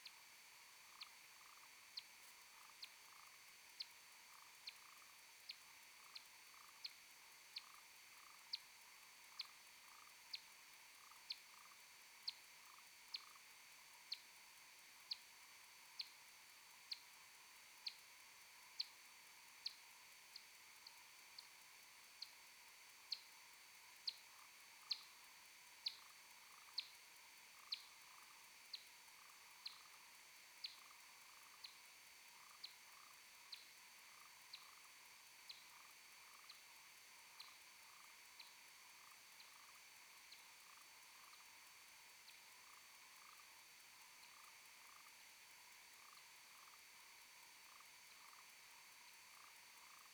Dossier 3 : expansion de temps x 10 :